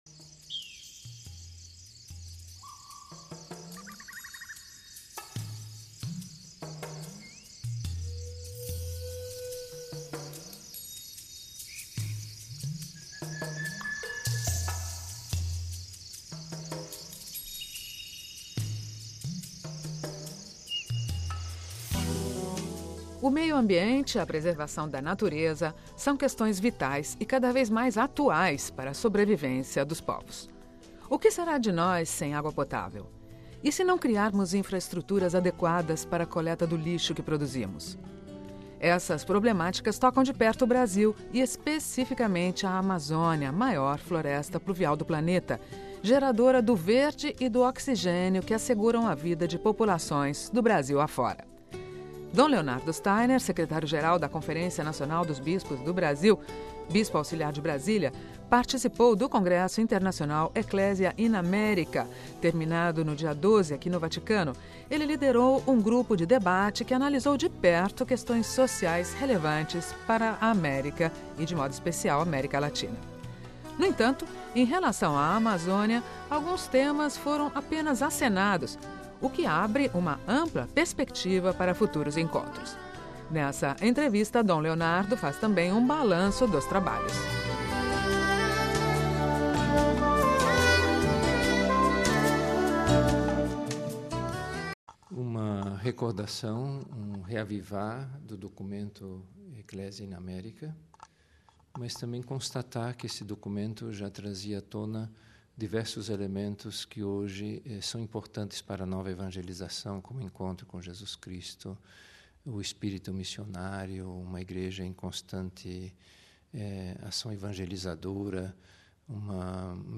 Nesta entrevista, Dom Leonardo faz também um balanço dos trabalhos.